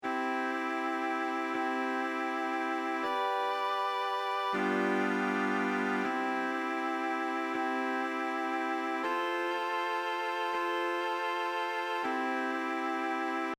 textual bahnhofplatz Im zugeordneten Tonraum werden für die drei Gruppen «Melodien» komponiert.
schauenden und staunenden Ortsunkundigen sind gemächlich, ruhig.
klingender_bahnhofplatz_schauen.mp3